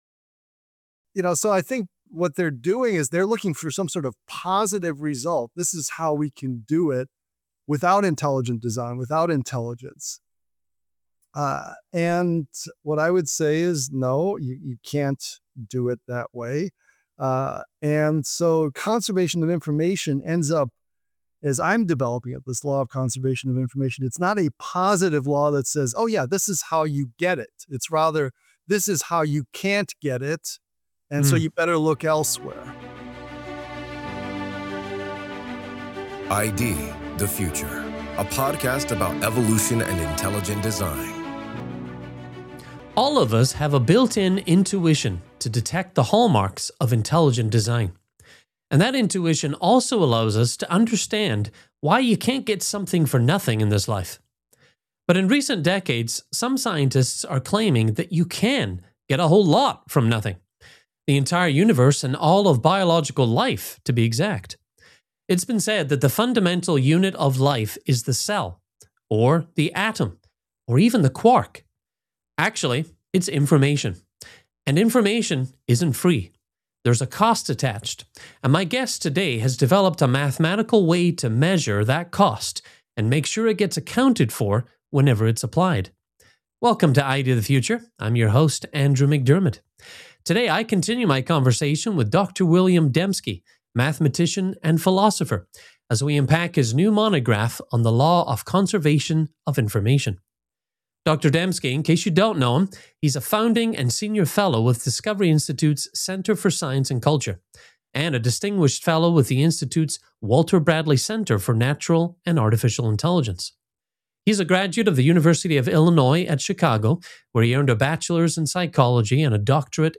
This is Part 2 of a four-part conversation.